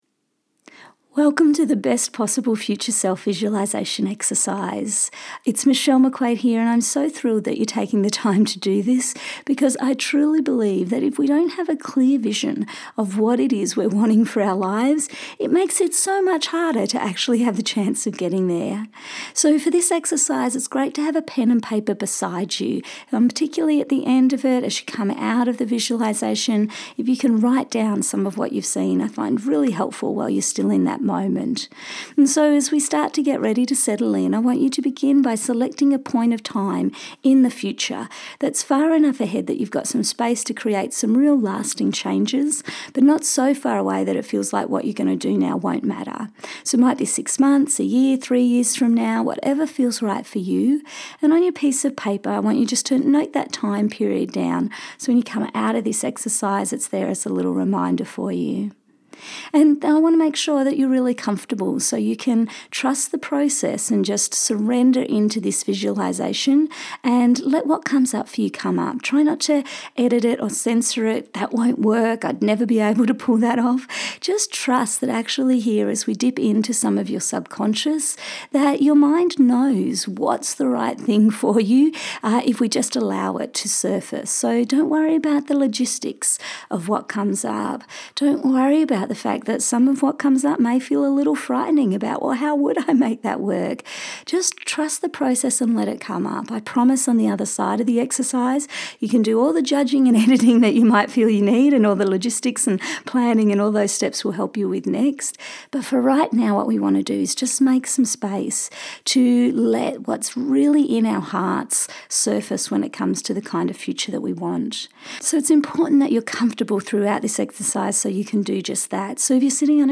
Try visualization meditations (